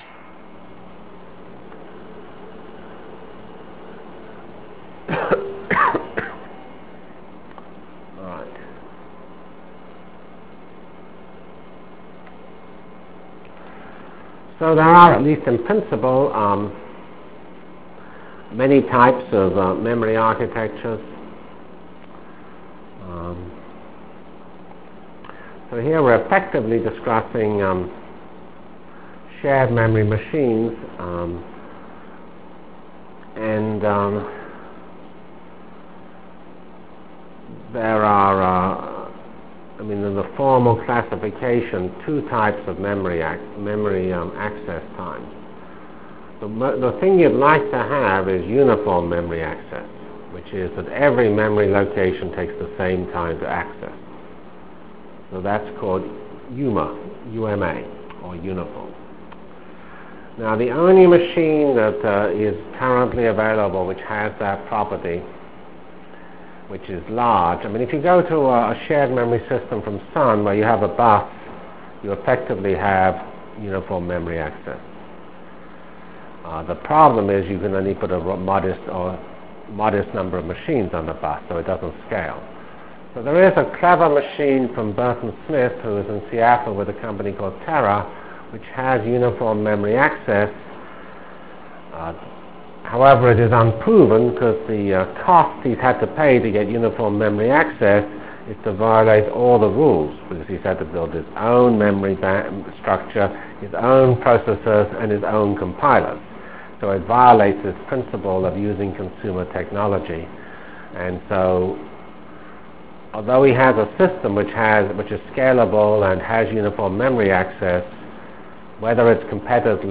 Delivered Lectures of CPS615 Basic Simulation Track for Computational Science -- 10 September 96.